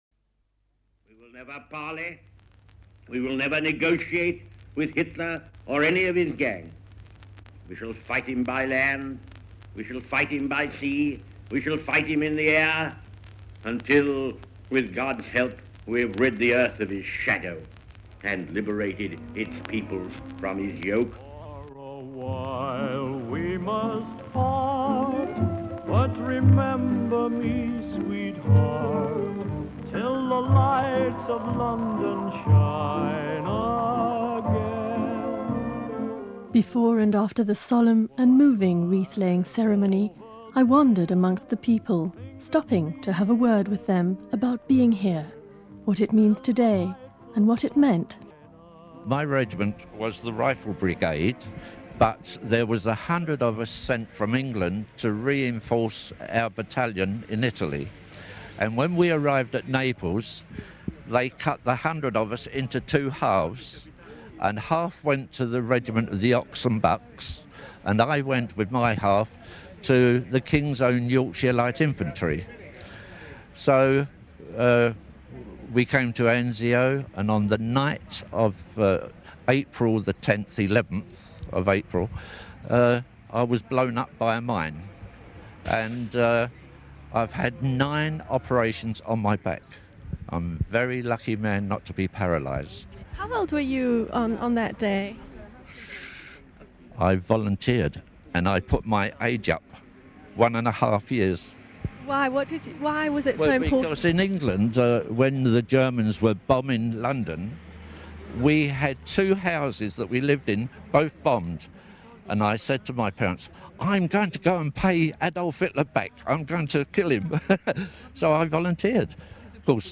Although their voices do not have the force of youth, but betray the toll of time and suffering on the human body, they are powerful testimonies of why it is important never to forget….